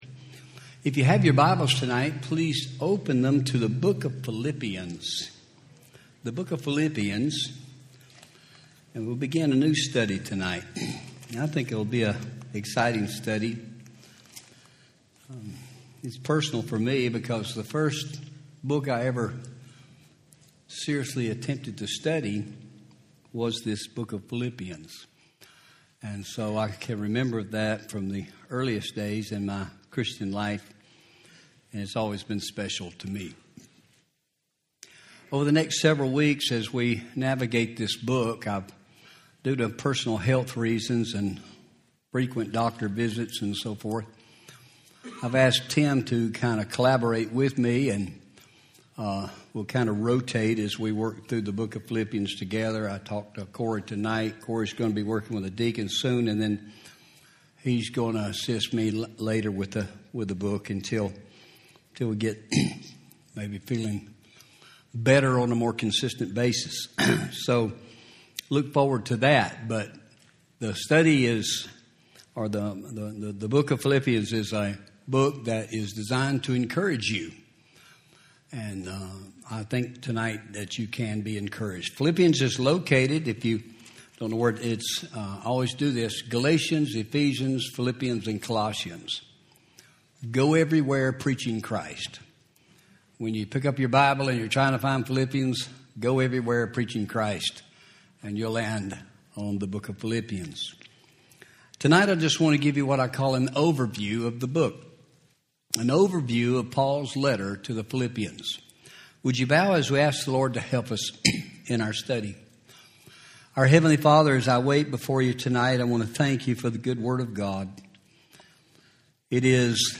Home › Sermons › Overview of Paul’s Letter to Philippians